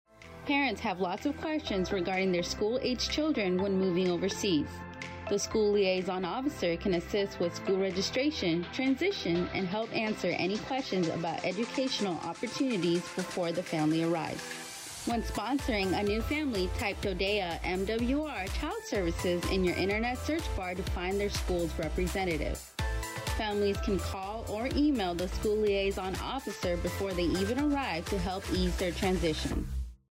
School Liaison Officer Radio Spot
Radio spot for information on School Liaison Officers. School liaison officers assist with transitions associated with military life and facilitate communication among school, parents and installation leaders.